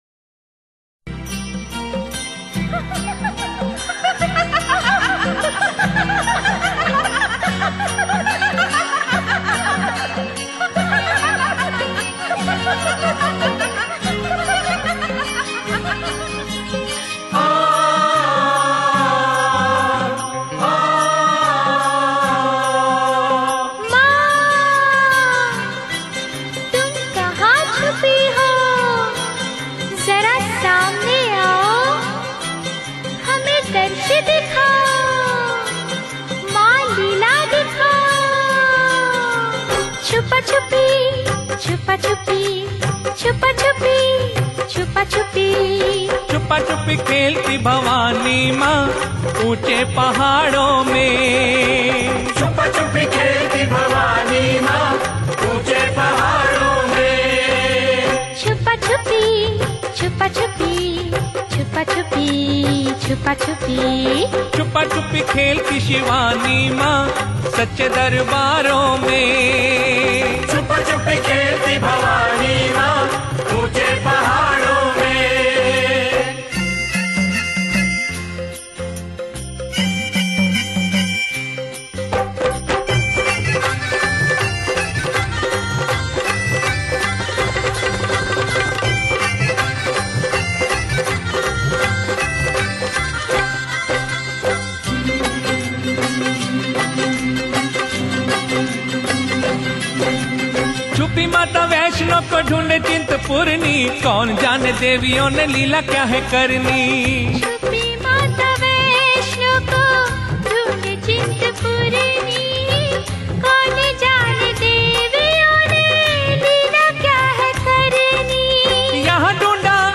Category: Bhakti Sangeet